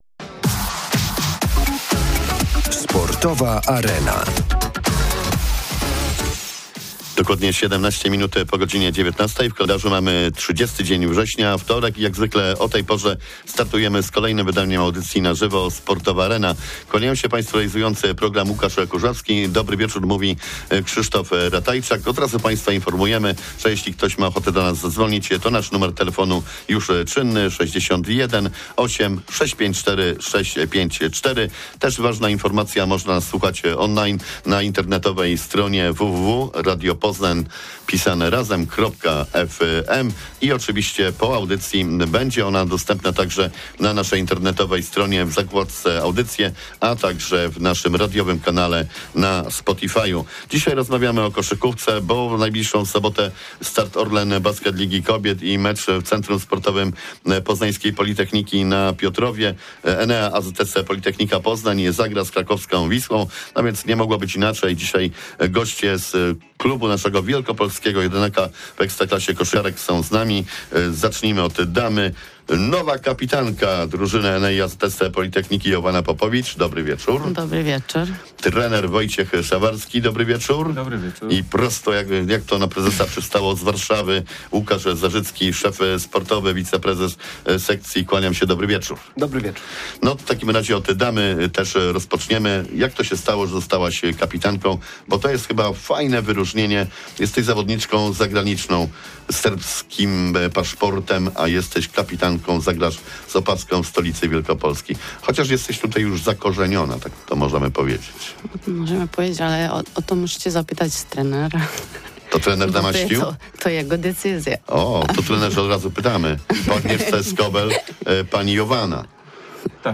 Sportowa Arena-live wtorek 30 września godz. 19.15. O koszykarkach Orlen Basket Ligi Kobiet z Enea AZS Politechnika Poznań.